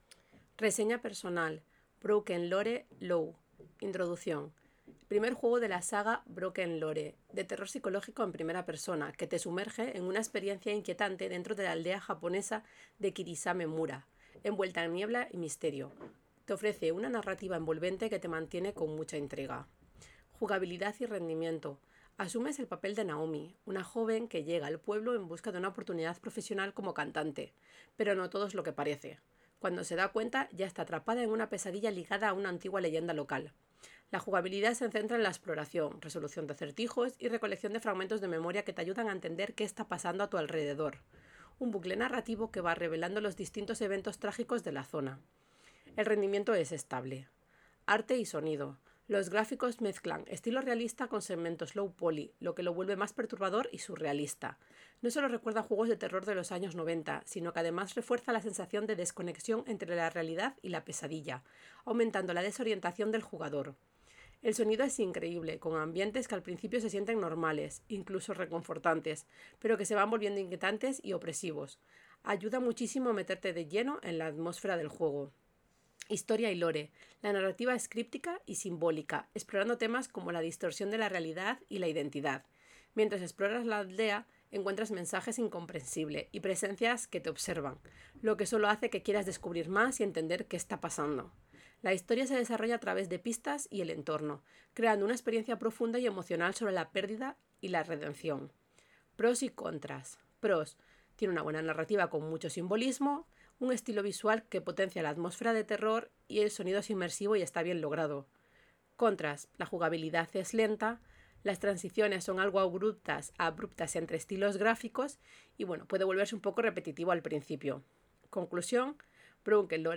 Reseña personal en audio: